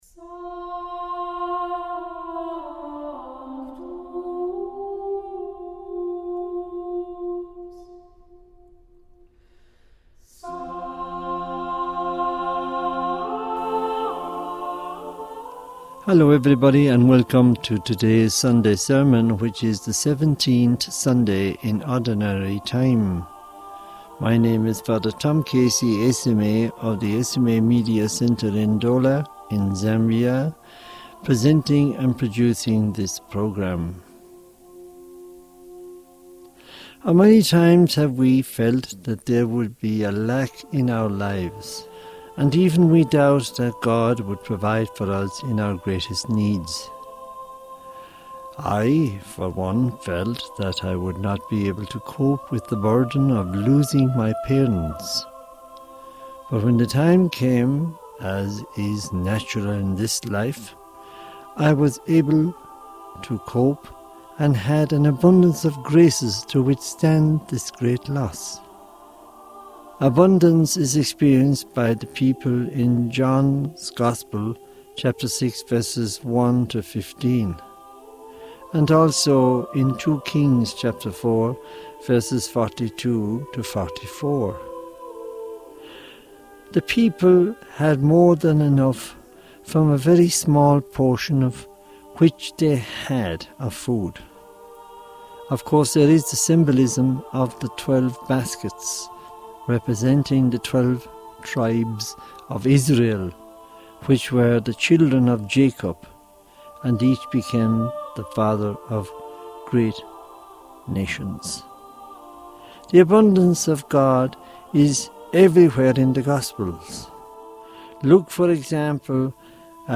Homily for the 17th Sunday of Ordinary Time 2024